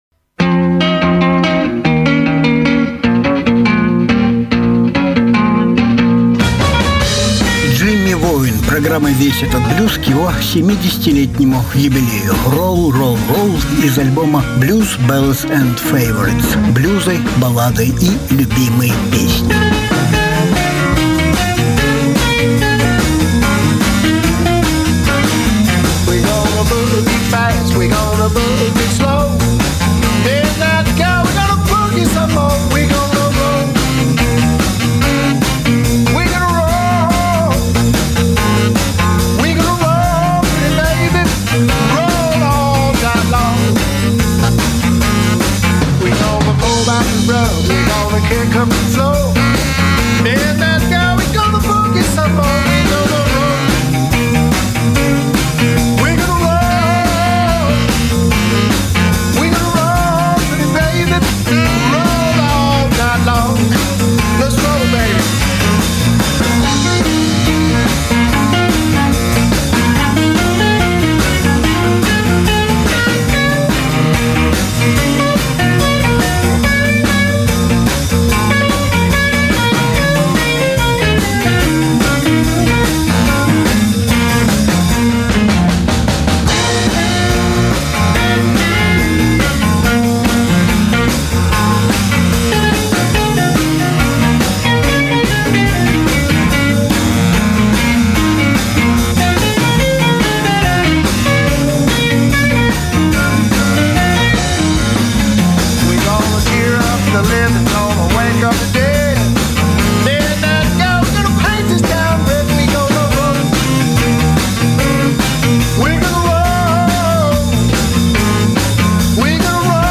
Жанр: Блюз